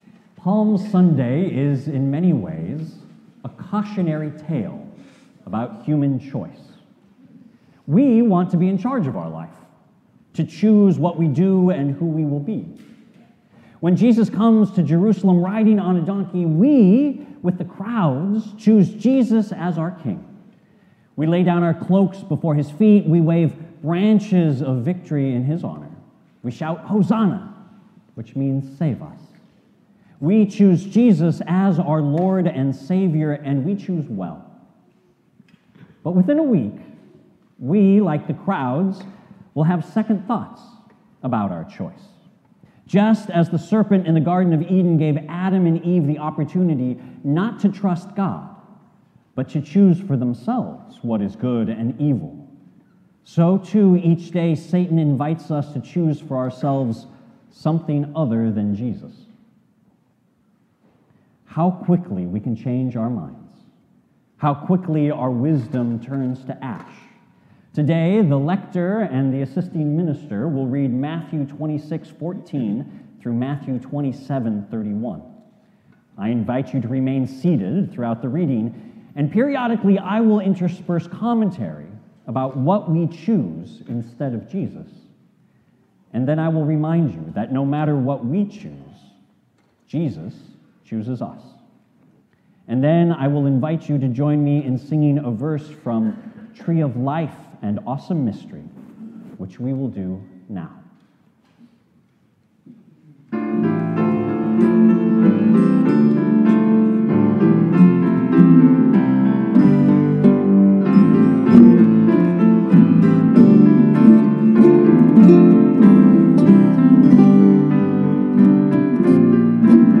As you listen to the sermon, here are some questions you can reflect on to deepen your spiritual connection with God: